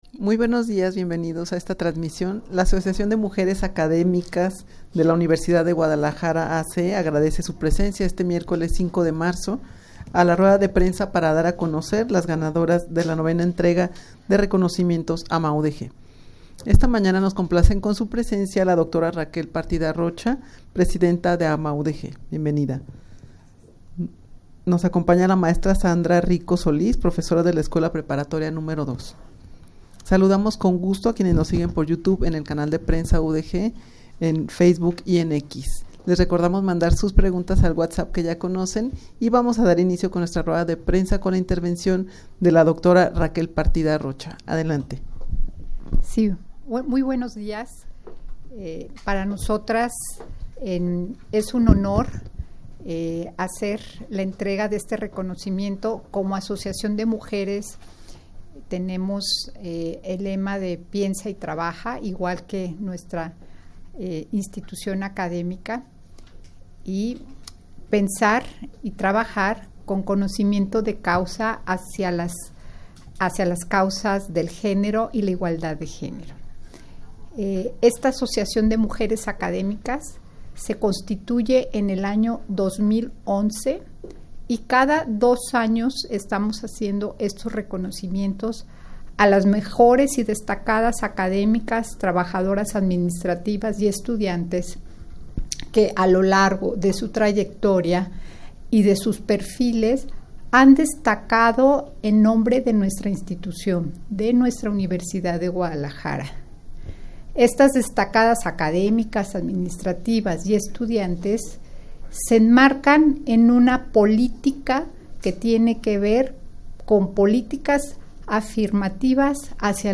rueda-de-prensa-para-dar-a-conocer-las-ganadoras-de-la-ix-entrega-de-reconocimientos-amaudeg.mp3